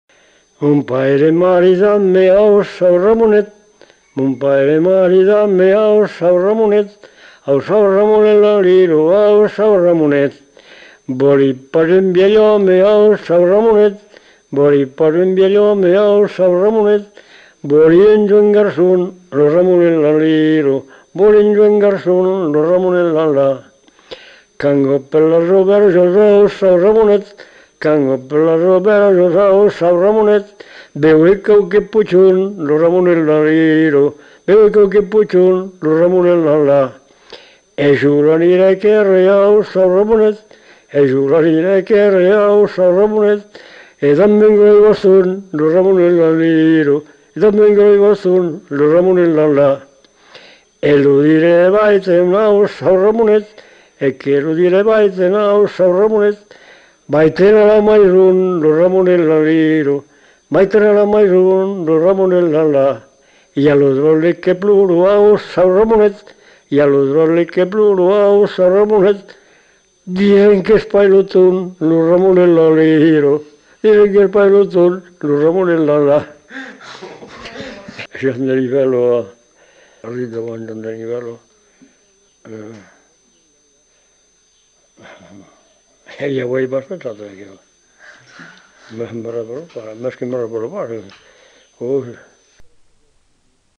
Lieu : Faudoas
Genre : chant
Effectif : 1
Type de voix : voix d'homme
Production du son : chanté